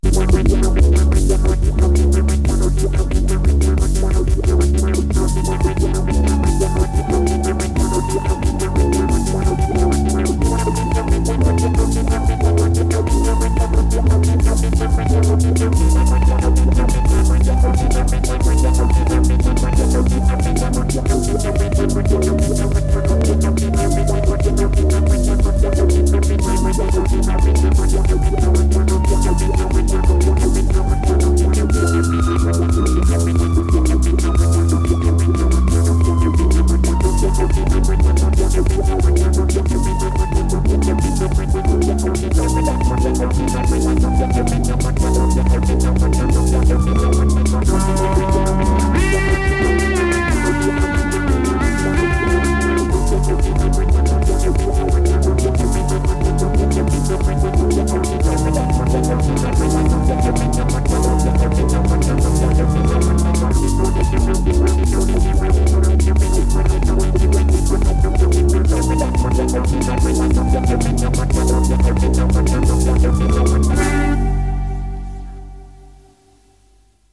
Incidental Music from the episode